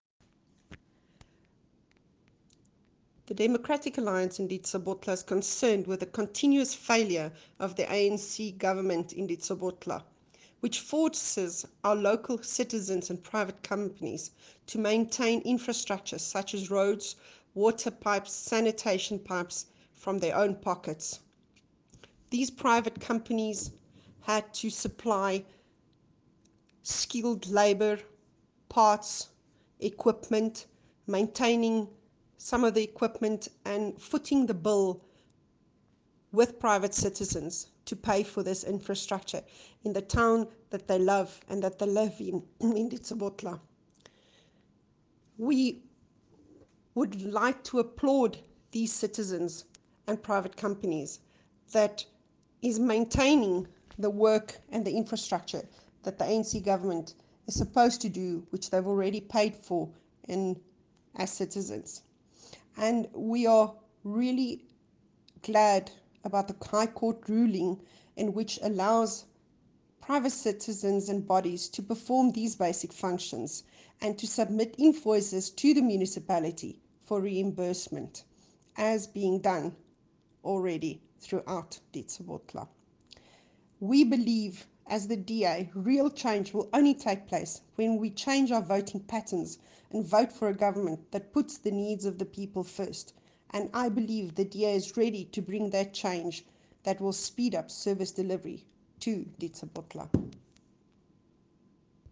Opmerking aan redakteurs: vind asseblief die aangehegte klankgrepe van die DA-kiesafdelingshoof, Jacqueline Theologo, MPL, in